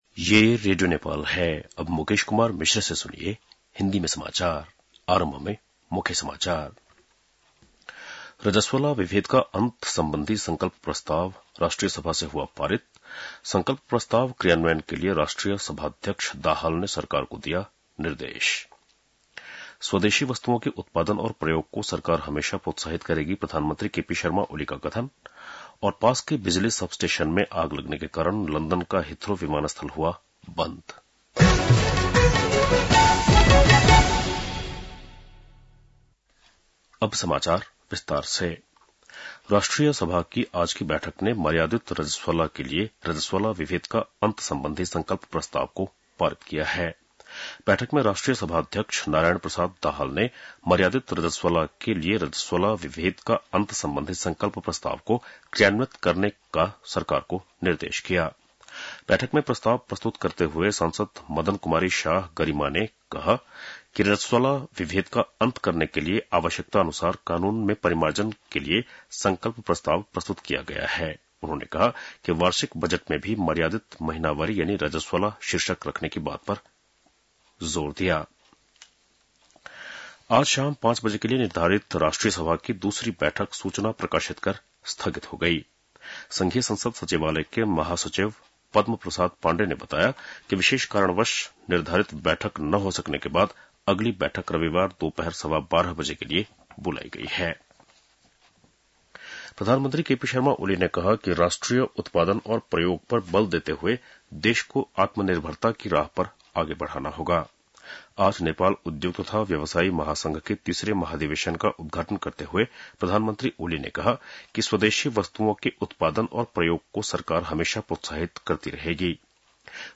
बेलुकी १० बजेको हिन्दी समाचार : ८ चैत , २०८१